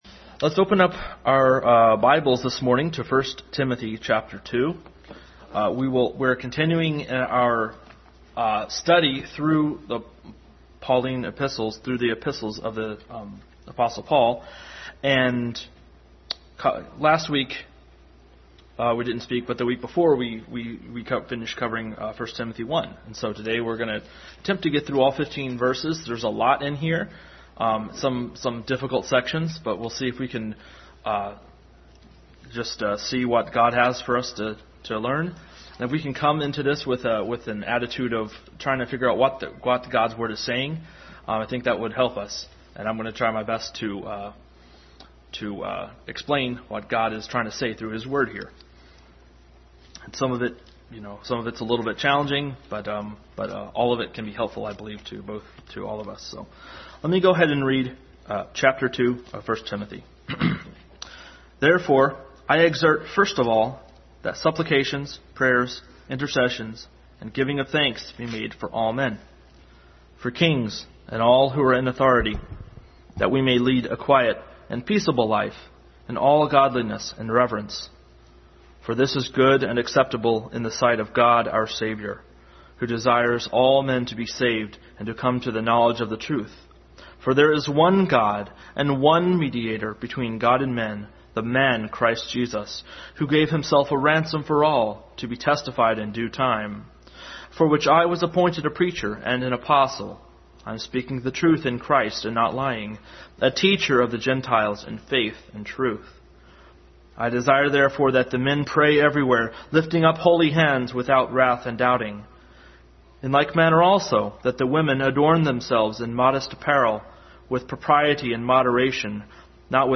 Adult Sunday School continued study in 1 Timothy.
1 Timothy 2:1-15 Service Type: Sunday School Adult Sunday School continued study in 1 Timothy.